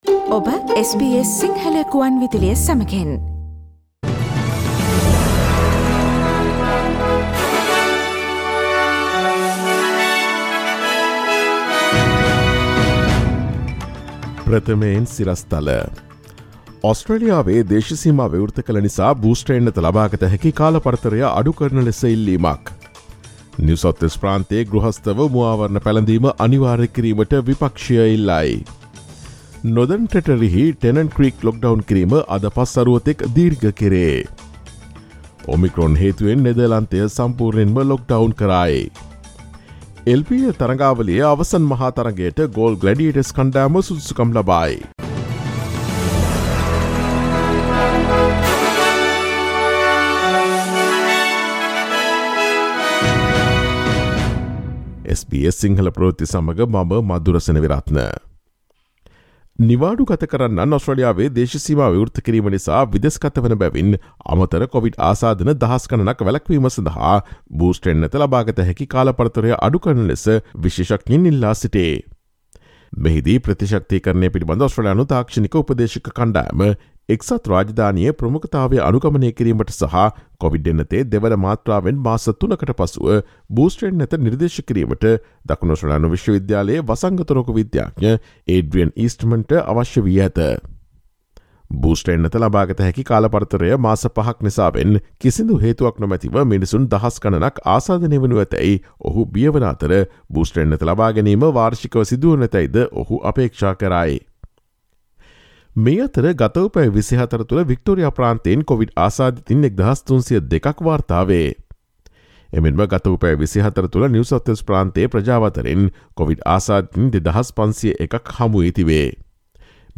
දෙසැ 20 දා SBS සිංහල ප්‍රවෘත්ති: ඔස්ට්‍රේලියාවේ බූස්ටර් එන්නත ලබාගත හැකි කාලපරතරය තවත් අඩු කරන ලෙස ඉල්ලීමක්
ඔස්ට්‍රේලියාවේ නවතම පුවත් මෙන්ම විදෙස් පුවත් සහ ක්‍රීඩා පුවත් රැගත් SBS සිංහල සේවයේ 2021 දෙසැම්බර් 20 වන දා සඳුදා වැඩසටහනේ ප්‍රවෘත්ති ප්‍රකාශයට සවන් දීමට ඉහත ඡායාරූපය මත ඇති speaker සලකුණ මත click කරන්න.